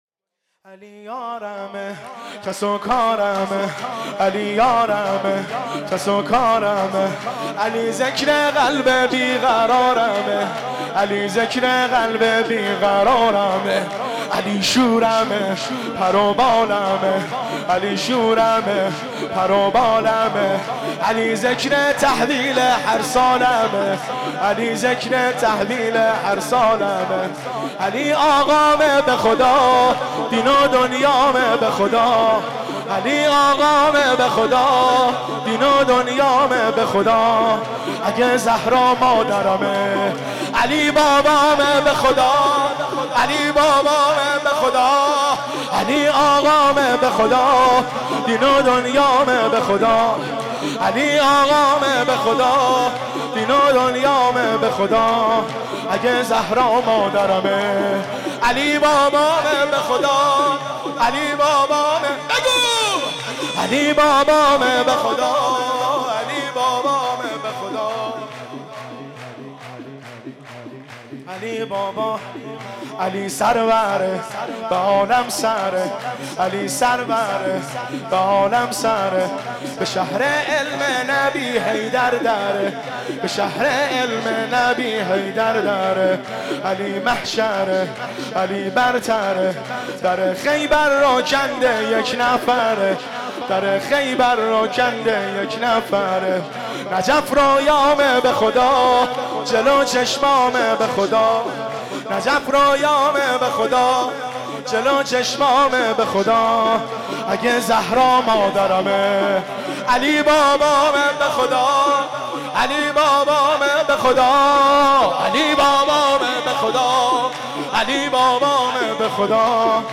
با مداحی